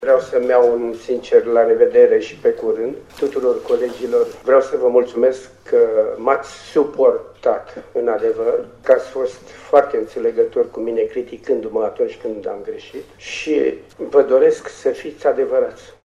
La finalul şedinţei, el le-a mulţumit colegilor pentru colaborare şi le-a urat să fie „adevăraţi”.